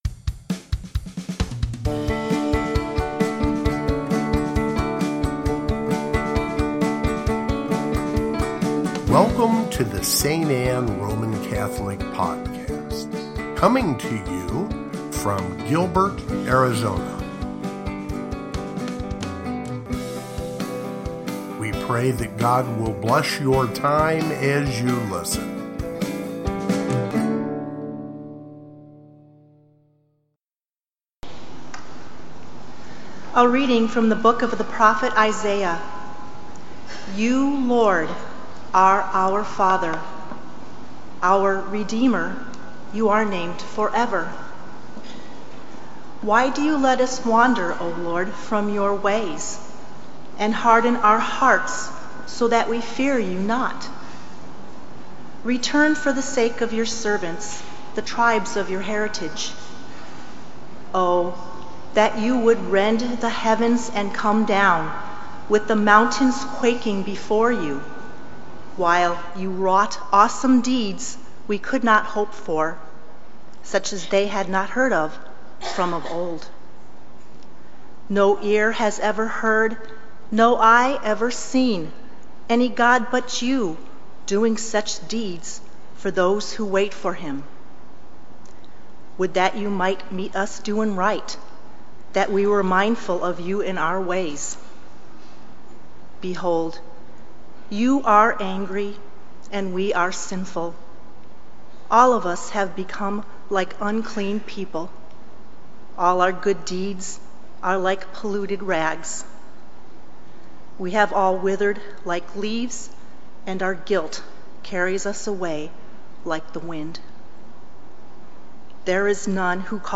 First Sunday of Advent (Readings)
Gospel, Readings, Advent